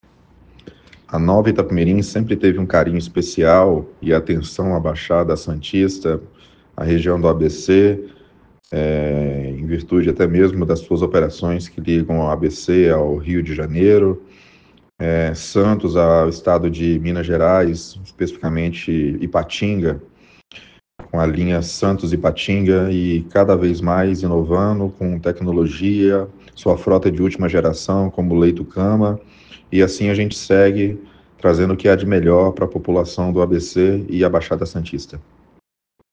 ENTREVISTA: Empresas rodoviárias destacam ABC como um dos principais polos de viagem para a Semana Santa/Tiradentes – 2025 – VEJA DICAS DE VIAGEM